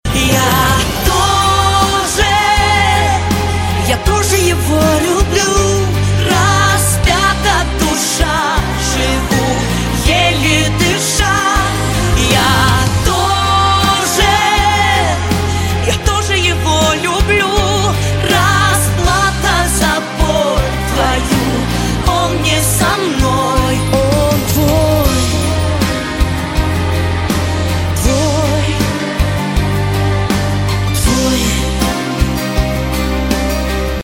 • Качество: 128, Stereo
поп
лирика
грустные